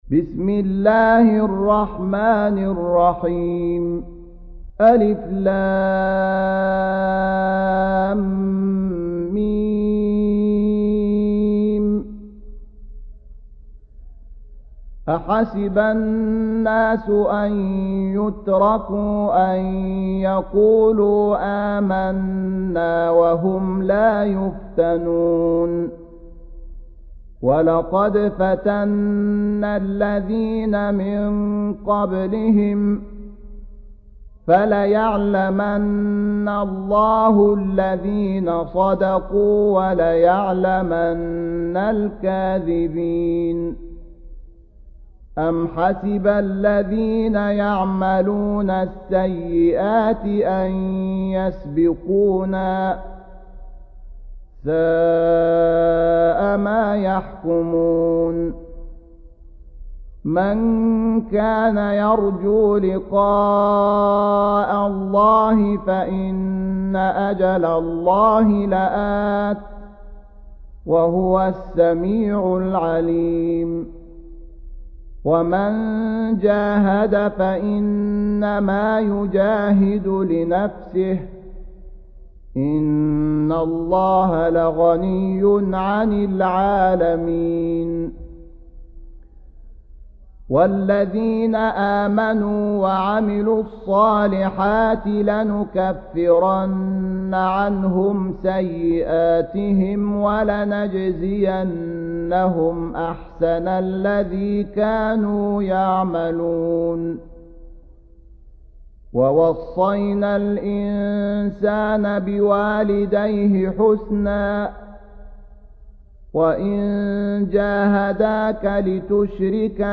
Surah Sequence تتابع السورة Download Surah حمّل السورة Reciting Murattalah Audio for 29. Surah Al-'Ankab�t سورة العنكبوت N.B *Surah Includes Al-Basmalah Reciters Sequents تتابع التلاوات Reciters Repeats تكرار التلاوات